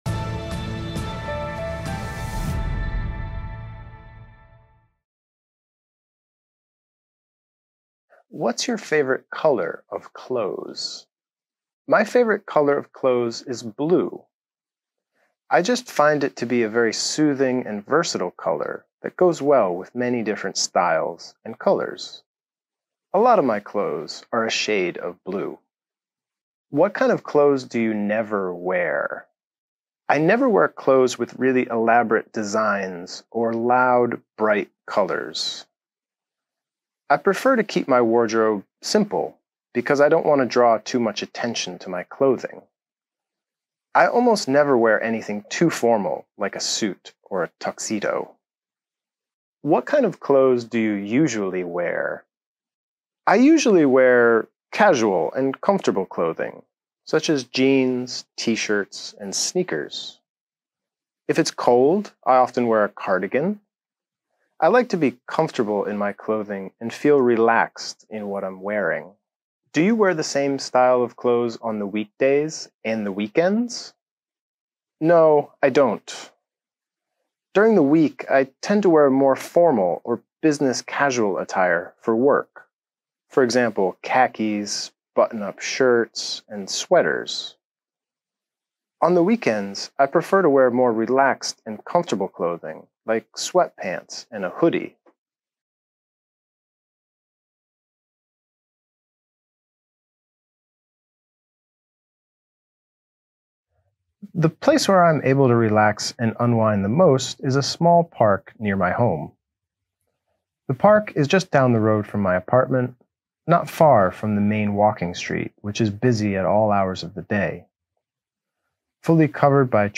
IELTS Speaking Test questions 1 - Sample Answer
IELTS_Speaking_Test_questions_1_-_Sample_Answer.mp3